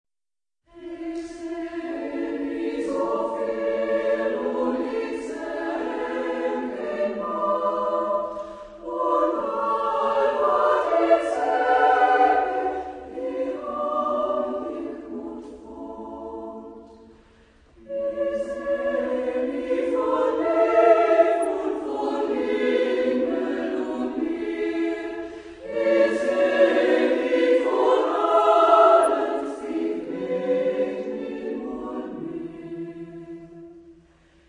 Chorgattung: Männerchor ODER Frauenchor
Aufnahme Bestellnummer: 4.Deutscher Chorwettbewerb, 1994